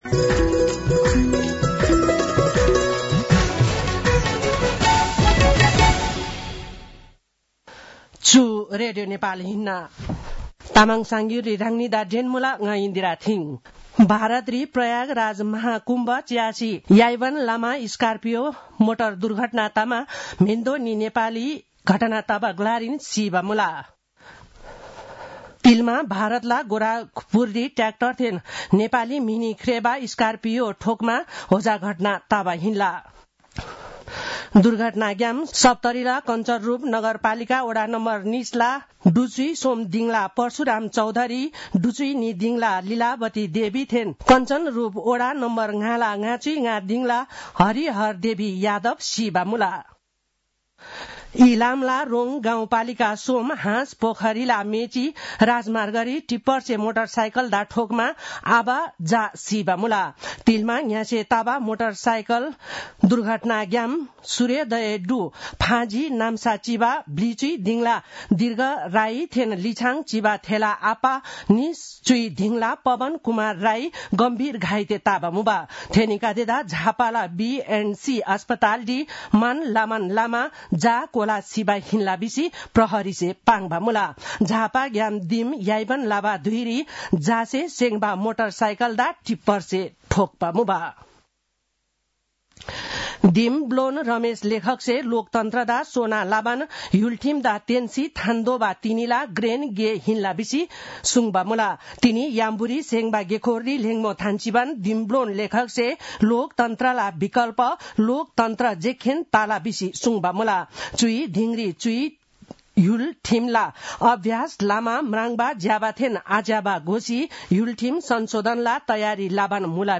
तामाङ भाषाको समाचार : ११ फागुन , २०८१